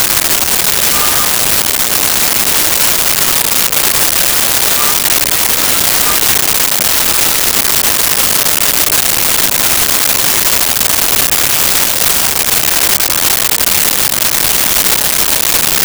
Restaurant Outdoor
Restaurant Outdoor.wav